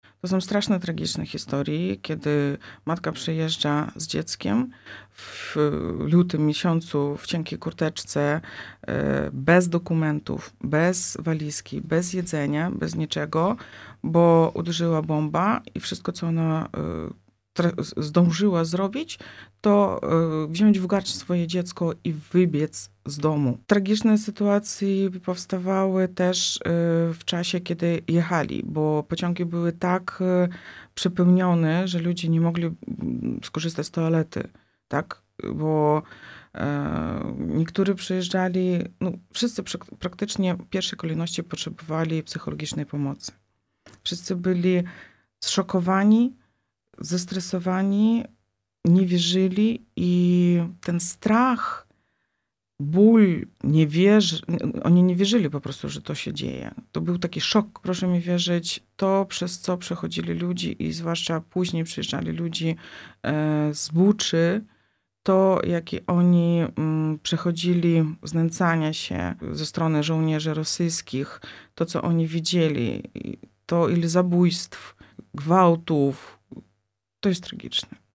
W rozmowie z Radiem Rodzina podzieliła się swoją pracą na rzecz uchodźców.